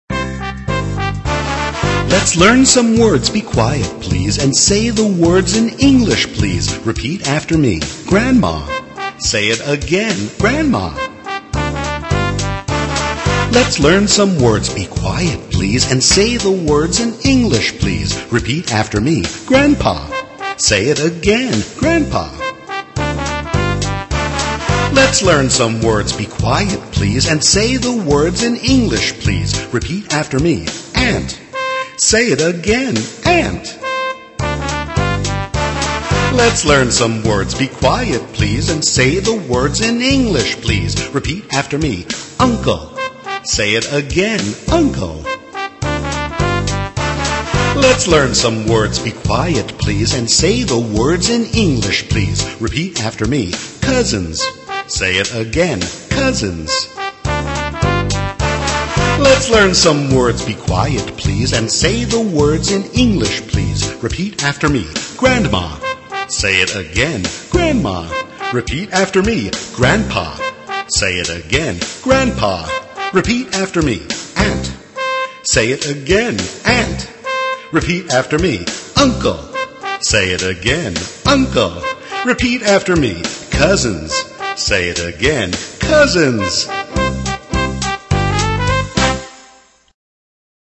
在线英语听力室英语儿歌274首 第116期:Let's learn some words的听力文件下载,收录了274首发音地道纯正，音乐节奏活泼动人的英文儿歌，从小培养对英语的爱好，为以后萌娃学习更多的英语知识，打下坚实的基础。